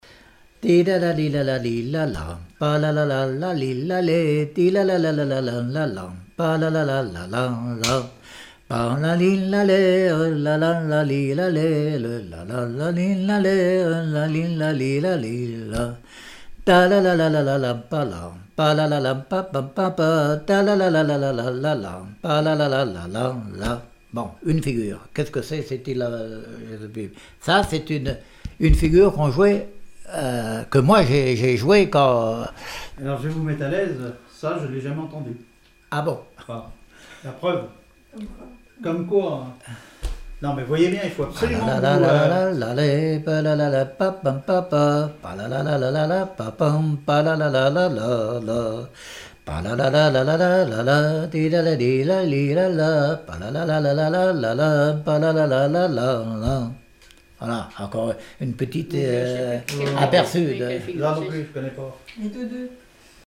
danse : quadrille
Témoignage comme joueur de clarinette
Pièce musicale inédite